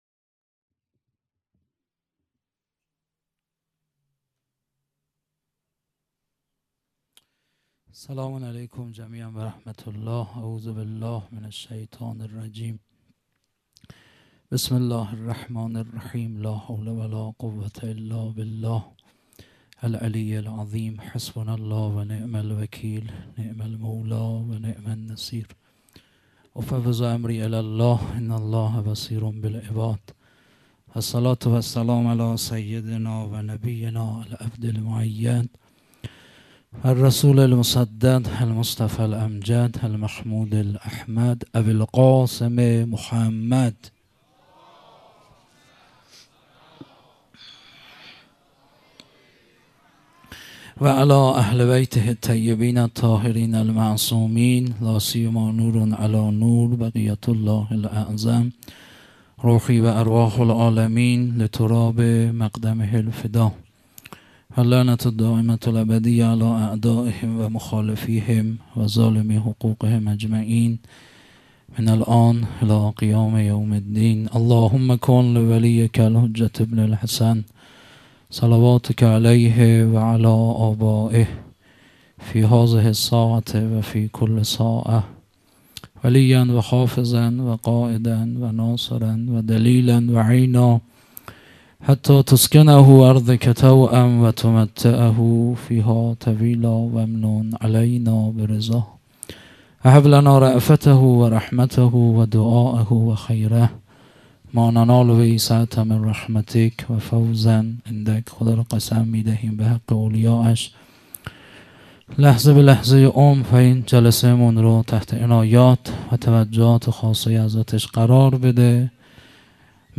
خیمه گاه - هیئت مکتب الزهرا(س)دارالعباده یزد - سخنران